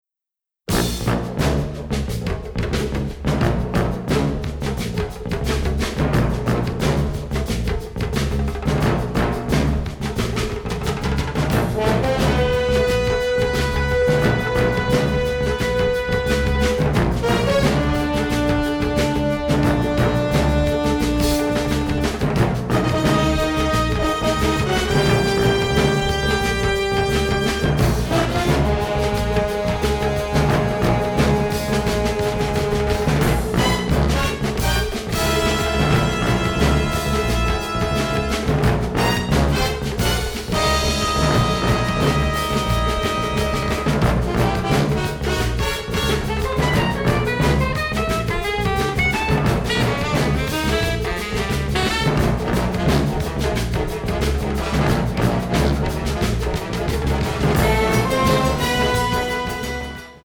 funk/jazz/groove